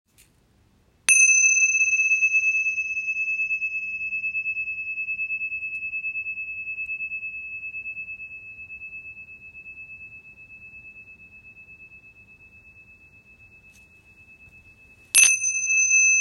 Tingsha Cymbals Mantra Design - 7cm
Tingsha are small, traditional Tibetan cymbals, handcrafted and joined by a leather strap or cord. When struck together, they produce a clear, high-pitched tone that is both bright and long-lasting.